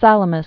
(sălə-mĭs, sälä-mēs)